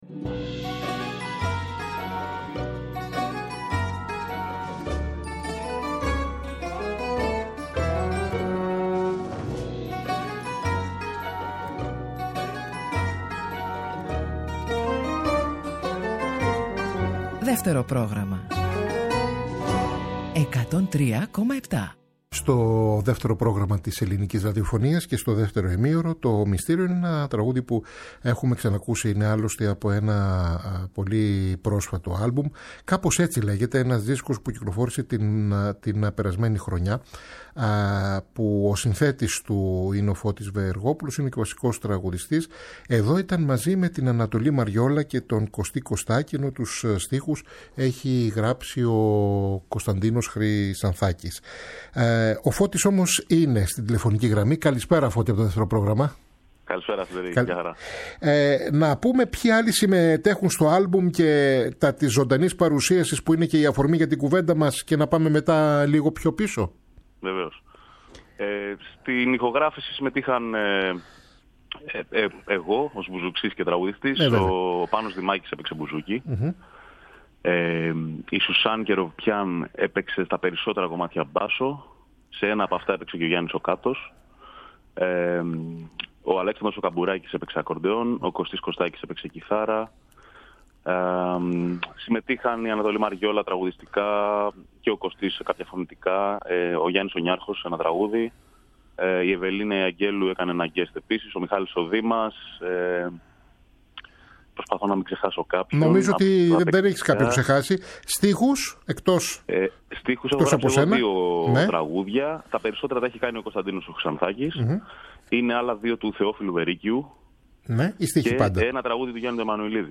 συνομιλεί τηλεφωνικά
Συνεντεύξεις